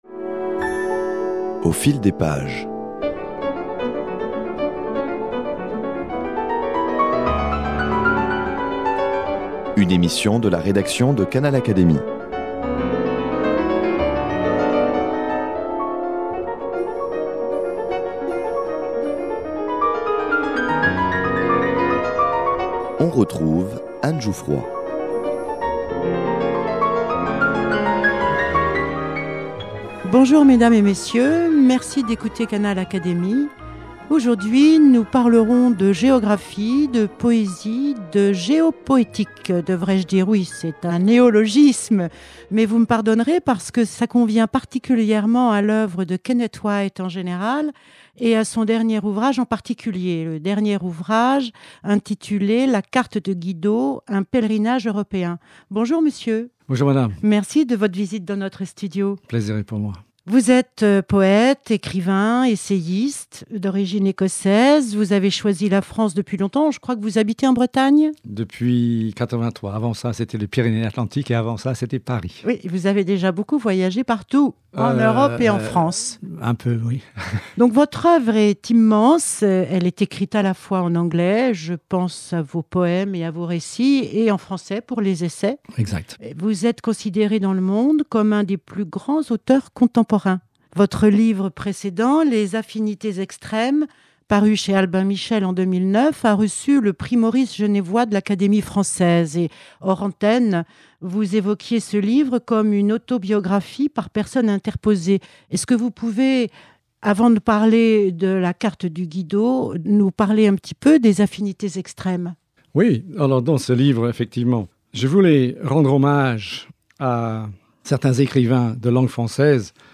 Le poète, écrivain et essayiste Kenneth White présente son livre La Carte de Guido, et autres pèlerinages européens : les balades poétiques et savoureuses d’un routard géographe, philosophe et malicieux.